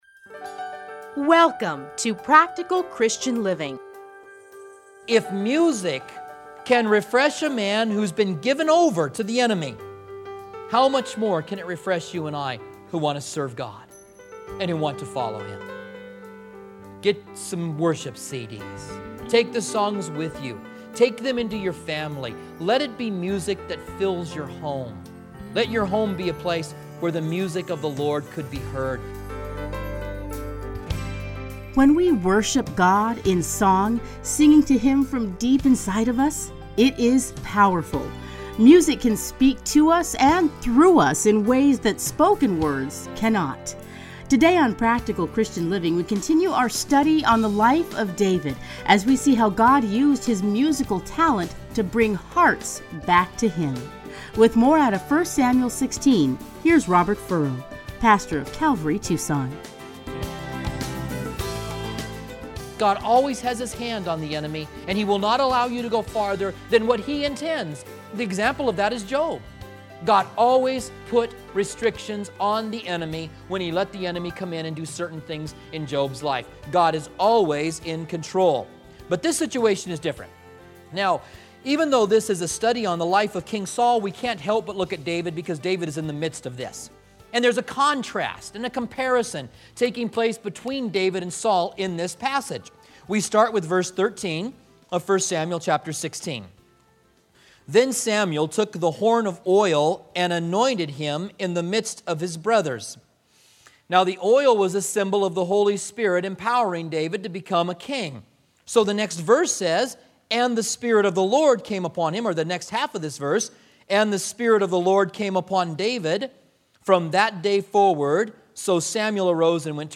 edited into 30-minute radio programs